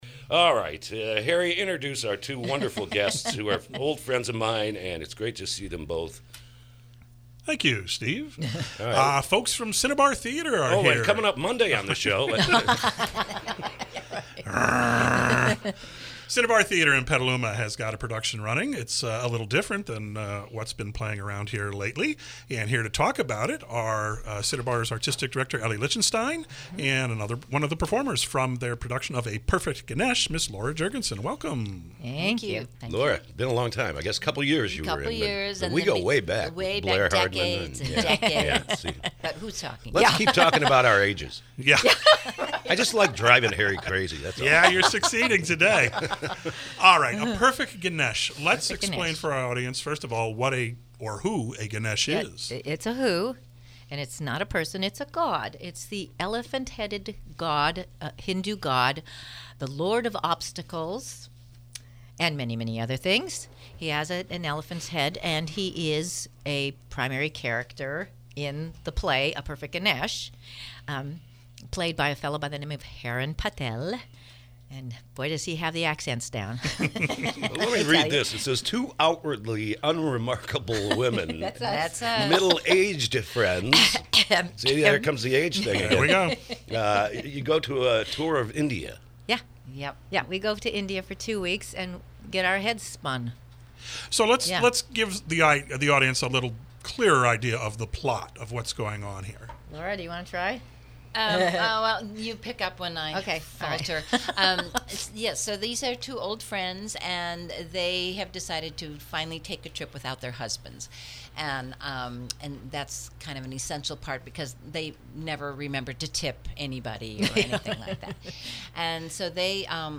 KSRO Interview – “A Perfect Ganesh”